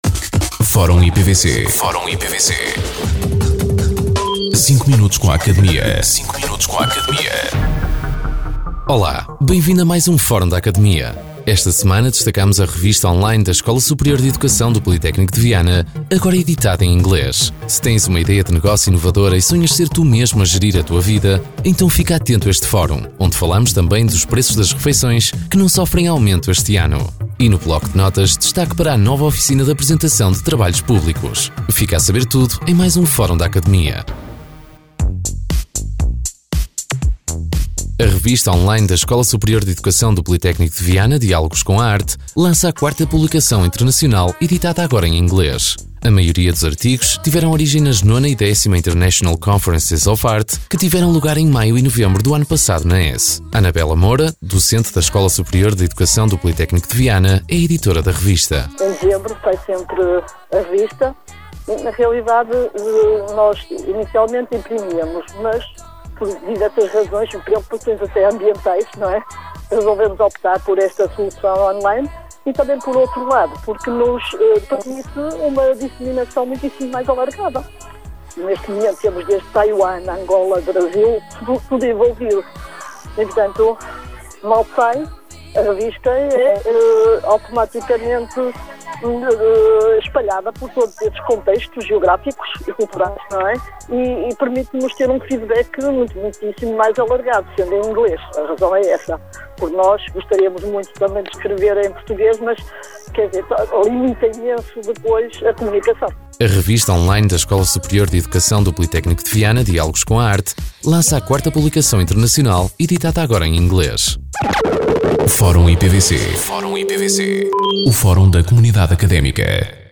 O Instituto Politécnico de Viana do Castelo [IPVC] tem mais um espaço radiofónico a ser transmitido, desta feita, na Rádio Caminha [RJC FM], em 106.2.
Entrevistados: